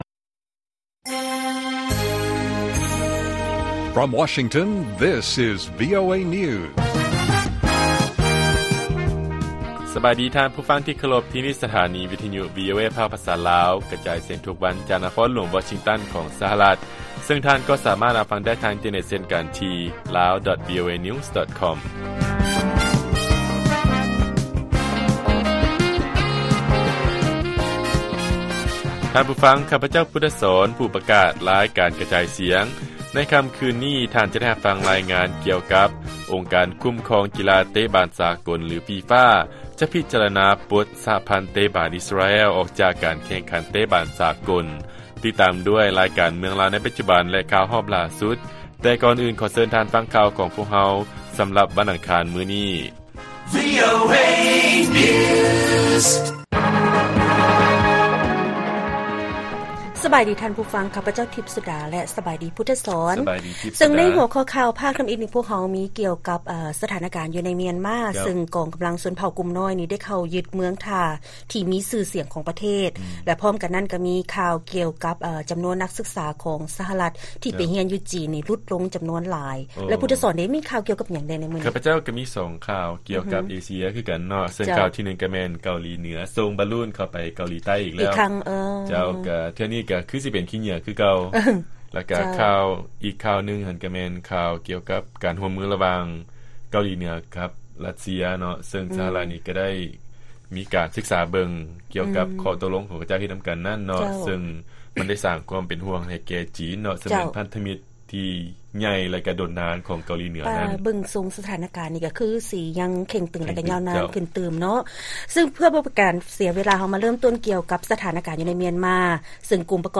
ລາຍການກະຈາຍສຽງຂອງວີໂອເອລາວ: ກຸ່ມຕິດອາວຸດຊົນເຜົ່າກຸ່ມນ້ອຍມຽນມາ ເຂົ້າຢຶດເມືອງທ່ອງທ່ຽວແຄມຊາຍຫາດຂອງປະເທດ